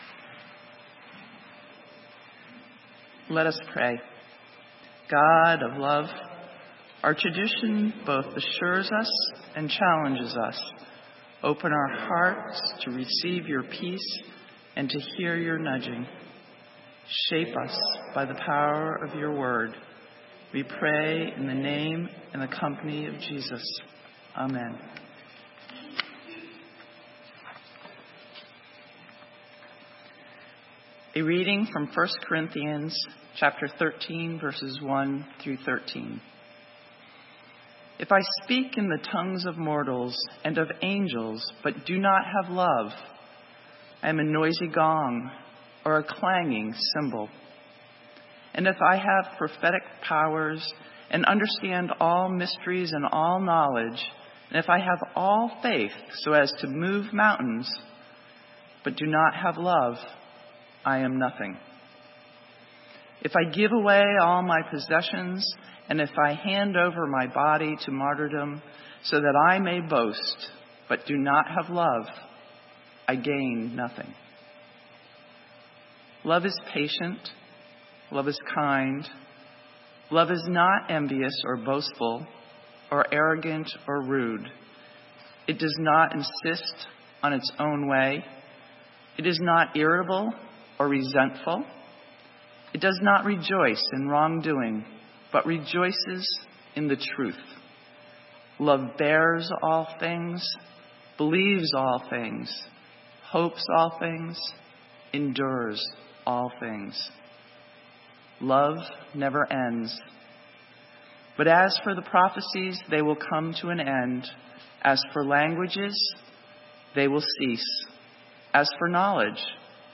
Sermon:Outsider God - St. Matthew's UMC